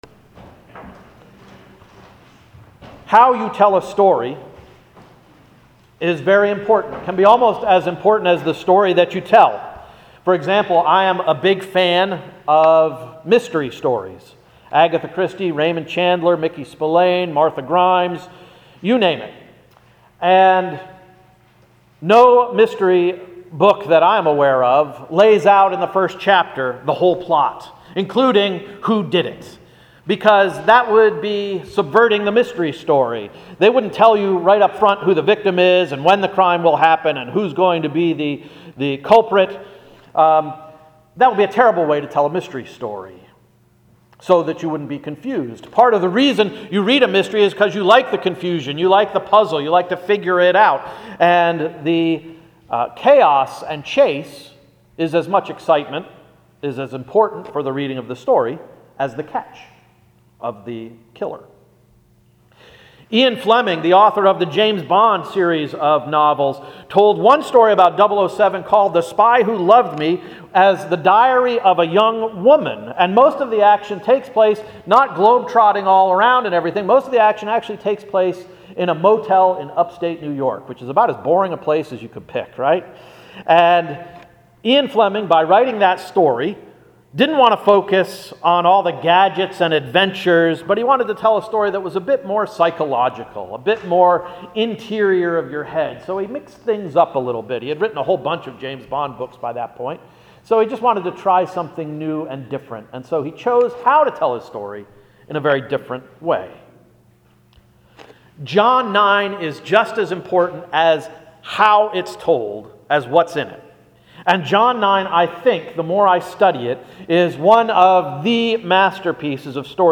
“Getting Our Hands Dirty” — Sermon of January 21, 2017